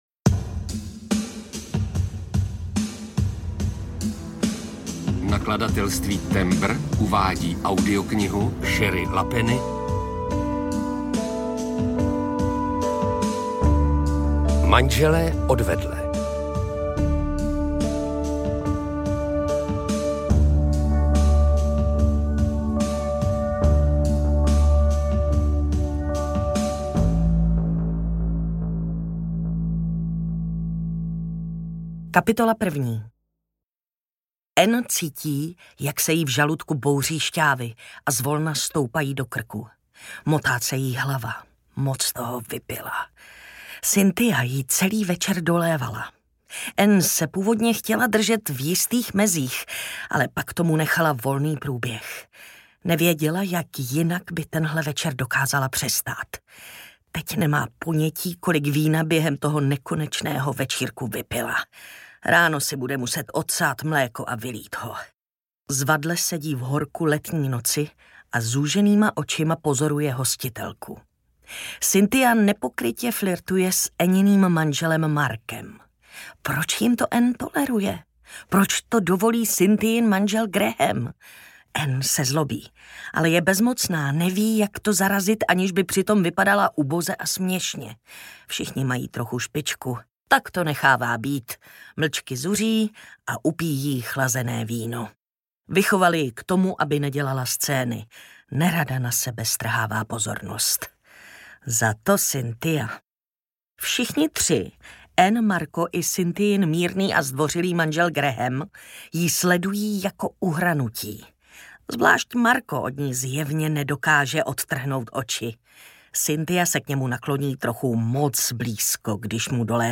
Manželé odvedle audiokniha
Ukázka z knihy